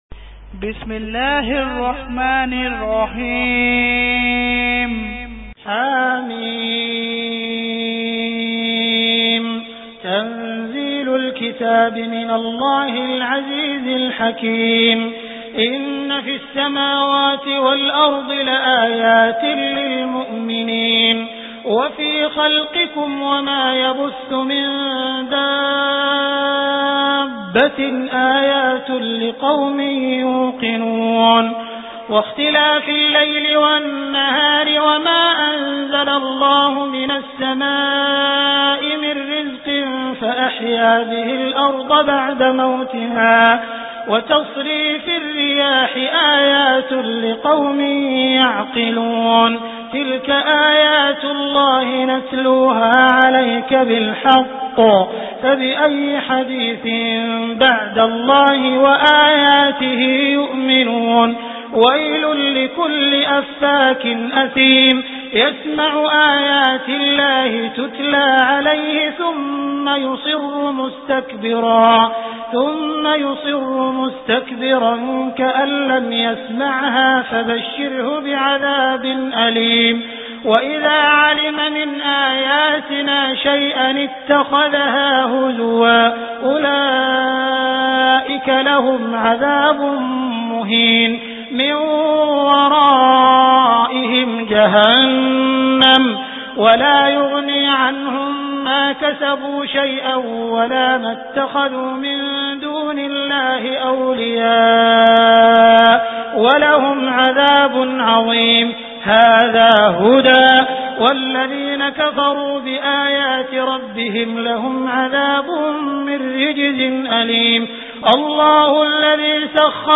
Surah Al Jathiya Beautiful Recitation MP3 Download By Abdul Rahman Al Sudais in best audio quality.